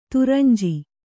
♪ turanji